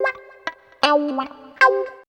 136 GTR 5.wav